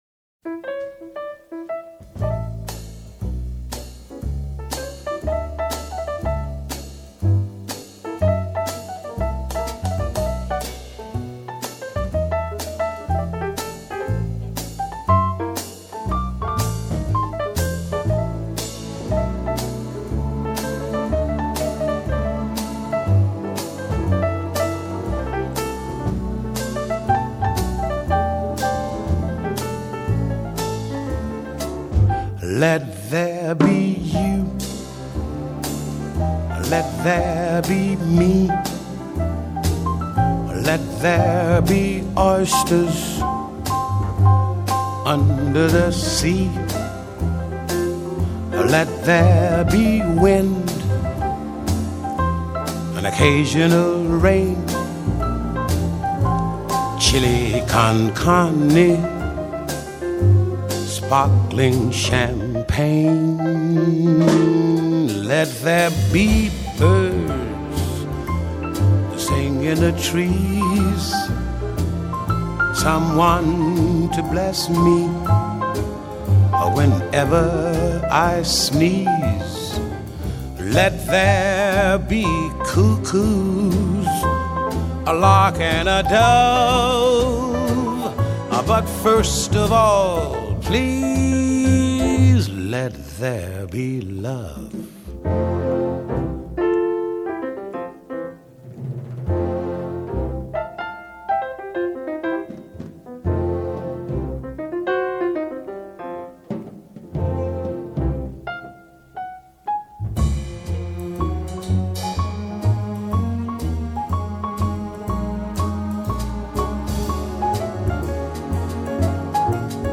Jazz, Pop